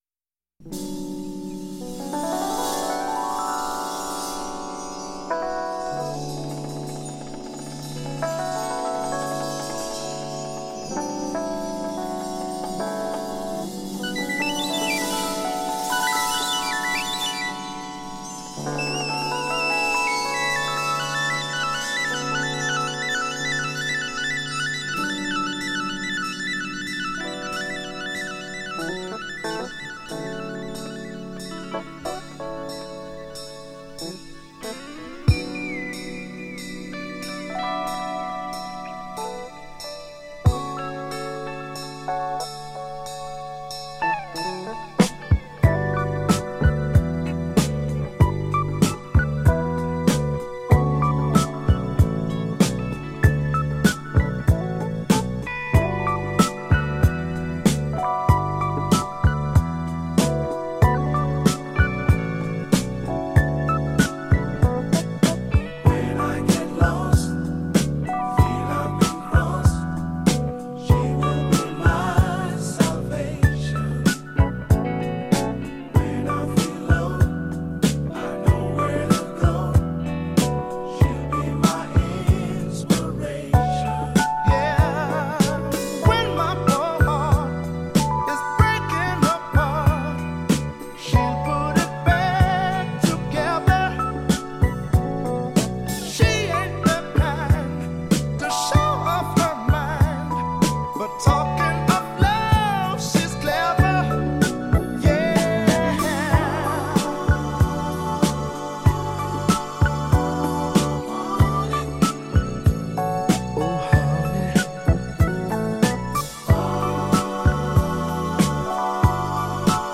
Another lost R&B gem